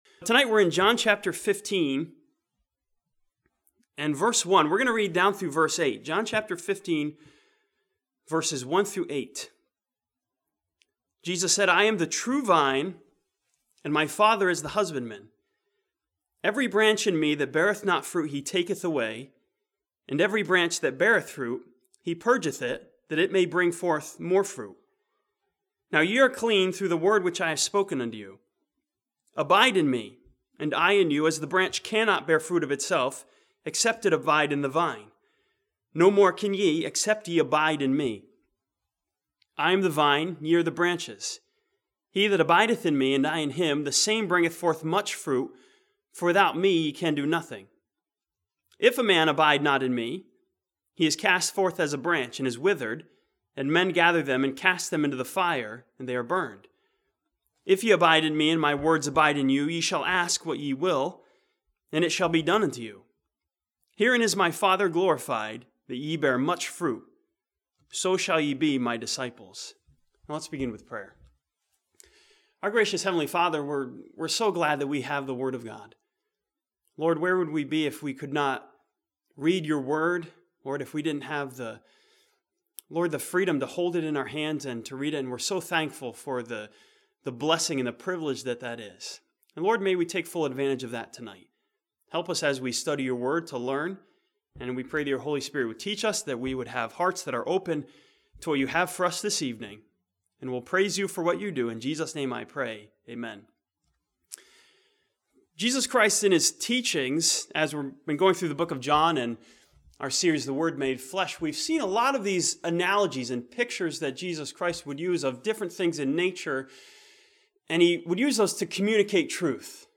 This sermon from John chapter 15 challenges Christians to produce Grade 1 fruit through the working of Christ and the Word of God.